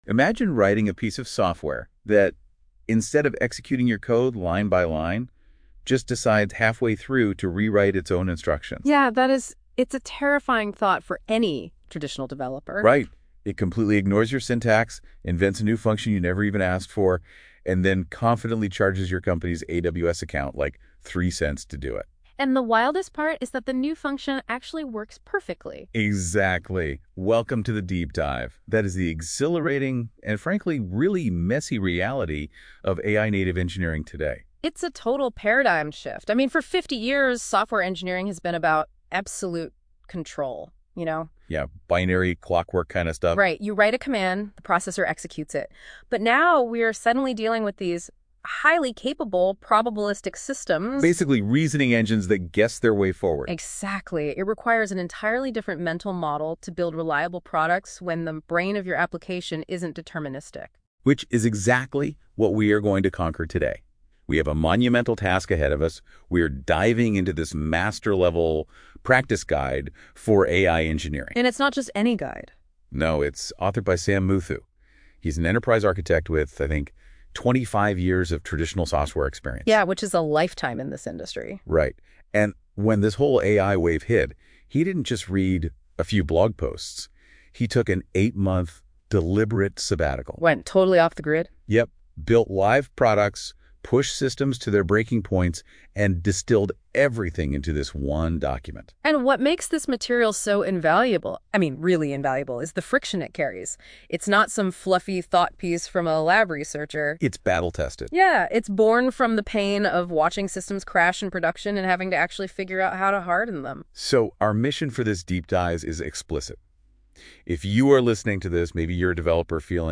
A two-host conversation walking through this entire page as a story — from the paradigm shift of probabilistic systems through to the production architecture that powers autonomous agents.